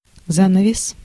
Ääntäminen
IPA: /duk/